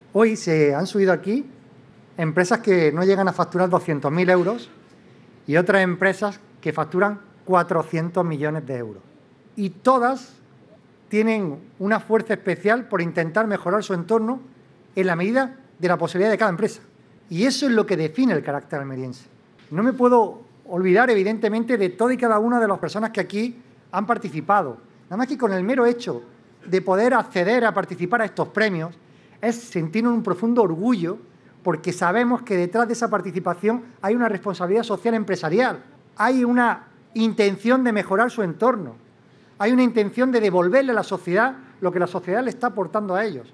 22-05_rse_presidente.mp3